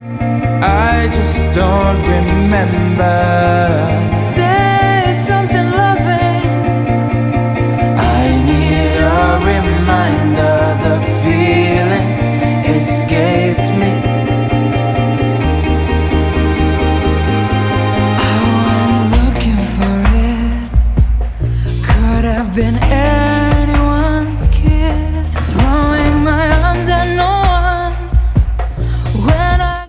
• Alternative